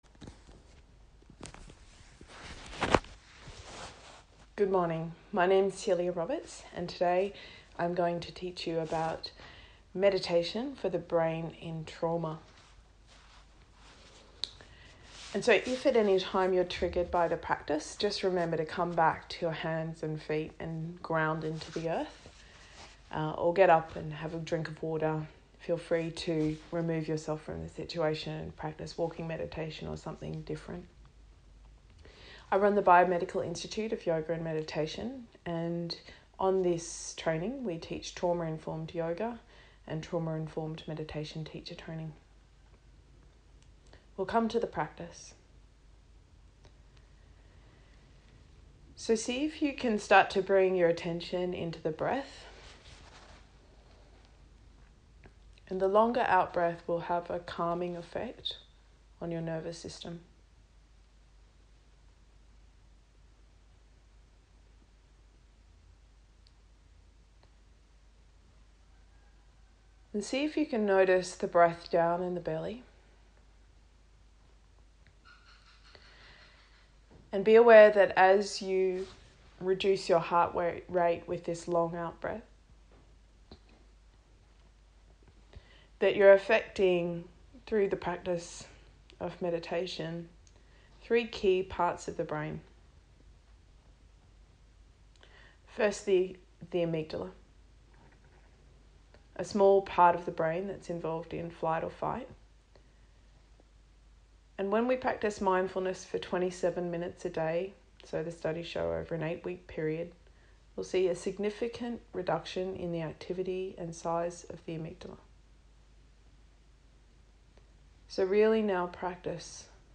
Primary Use: meditation, breathing, stress reduction
Primary practice: Buddhist meditation, Trauma Informed
Trauma-Informed-Meditation-Mindfulness-For-Neuroplastic-Change.mp3